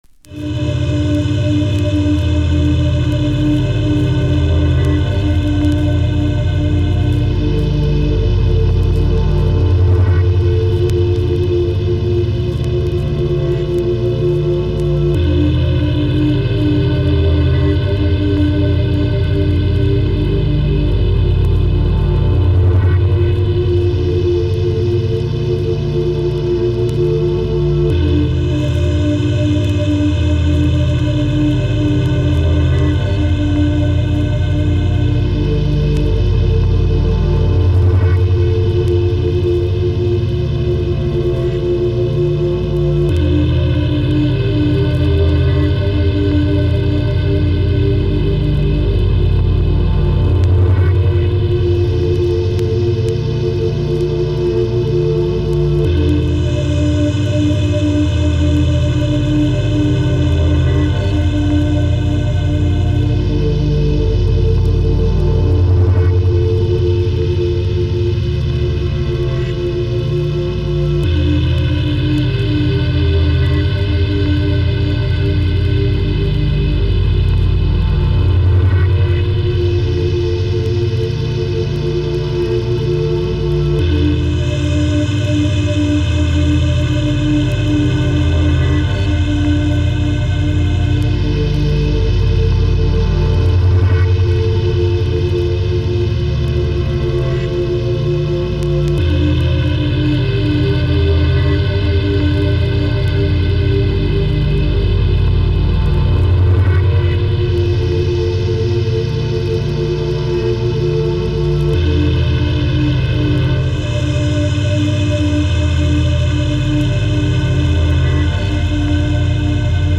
underground dance music